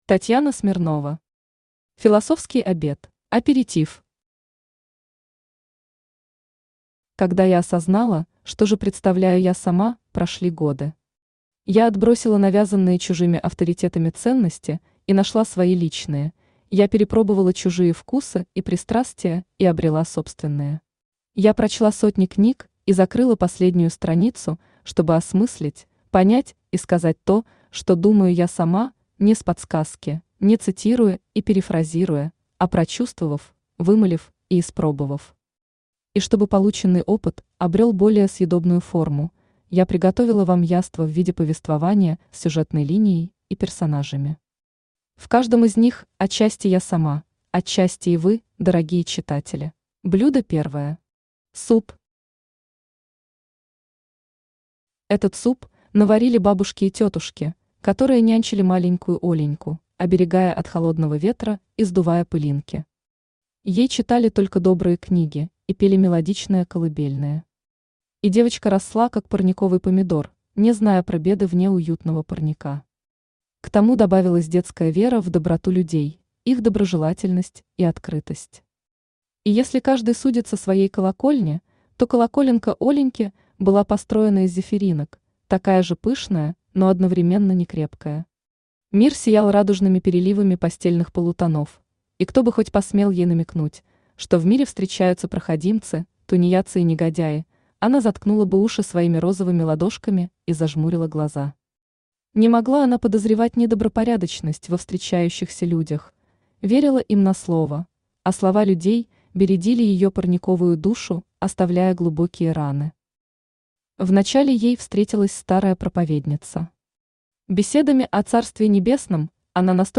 Aудиокнига Философский обед Автор Татьяна Андреевна Смирнова Читает аудиокнигу Авточтец ЛитРес.